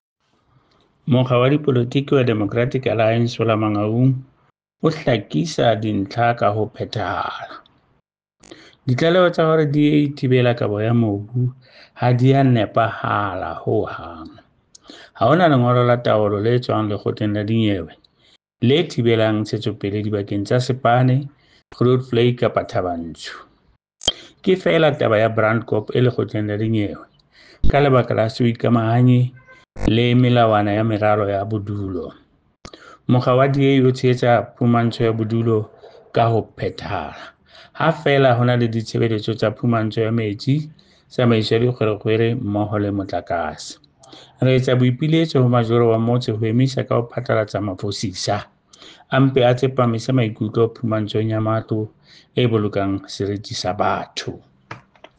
Sesotho soundbite by Cllr Edwin Maliela.